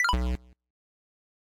beep_error.ogg